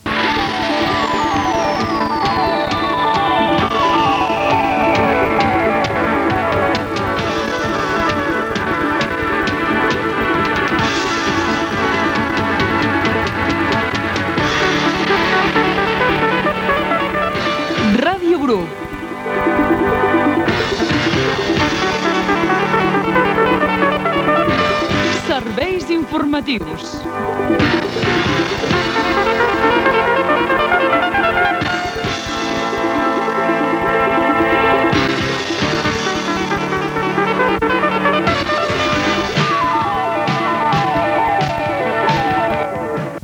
Careta serveis informatius